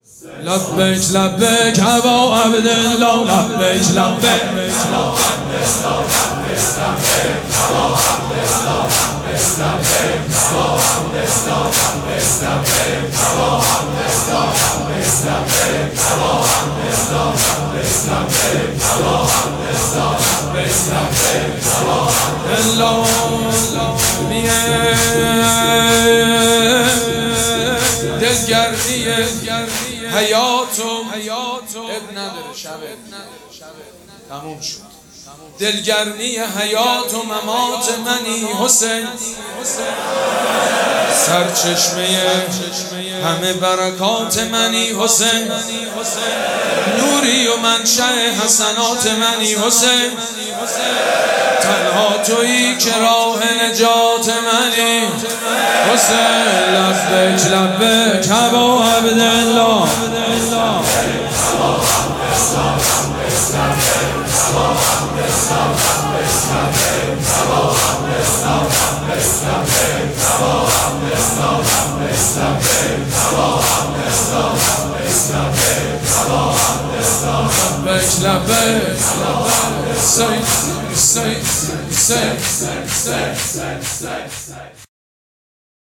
مراسم عزاداری شب دهم محرم الحرام ۱۴۴۷
مداح
حاج سید مجید بنی فاطمه